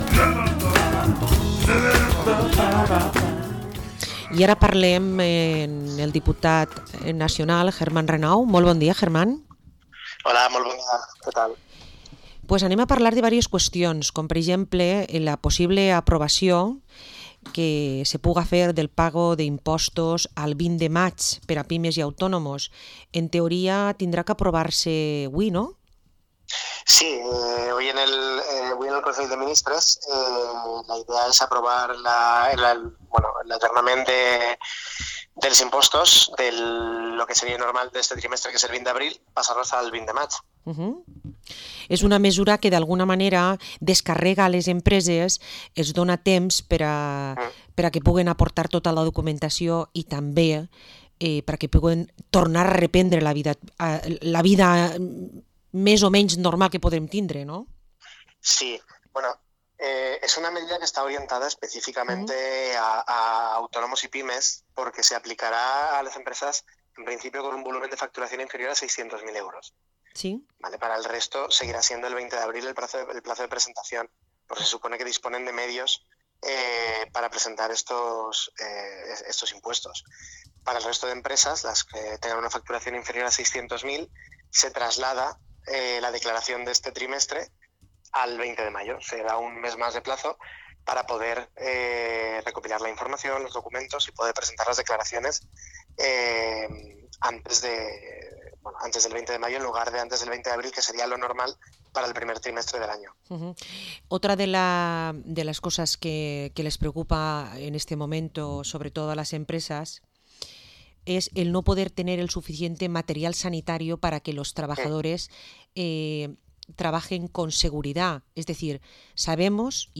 Entrevista al diputado nacional del PSPV-PSOE, German Renau